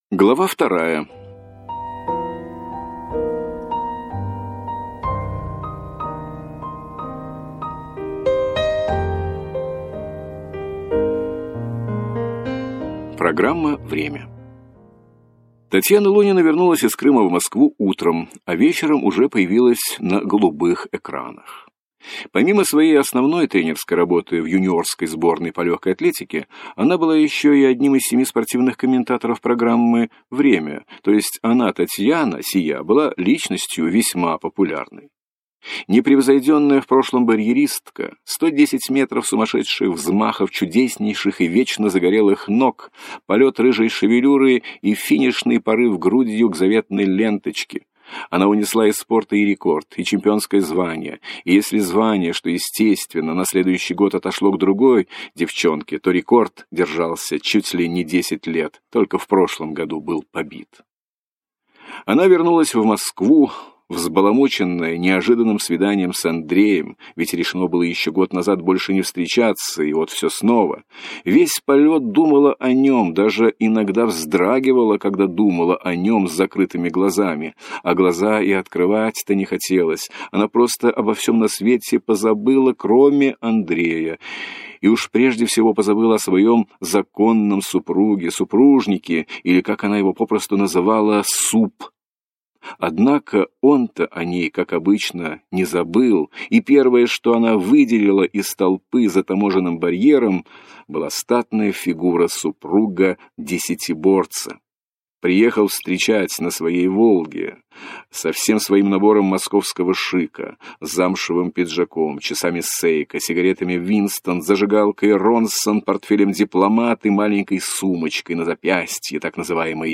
Аудиокнига Остров Крым | Библиотека аудиокниг
Прослушать и бесплатно скачать фрагмент аудиокниги